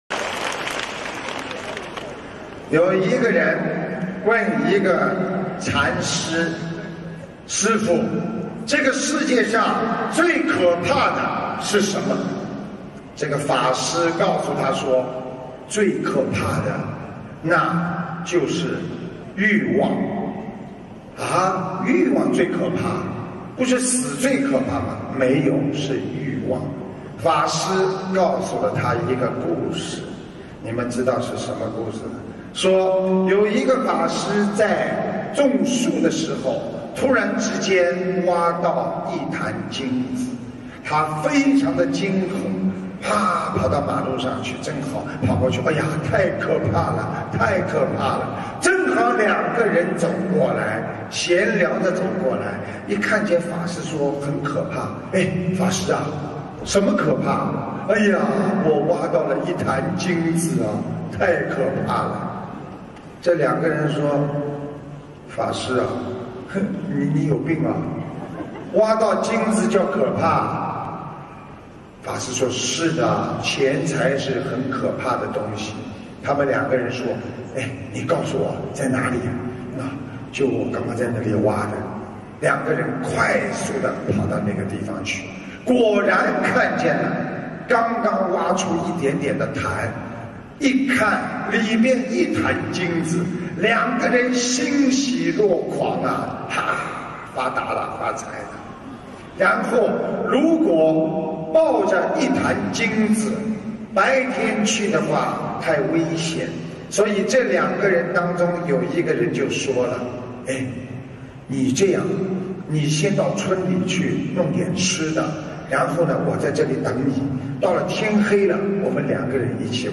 音频：这个世界上最可怕的是什么·师父讲小故事大道理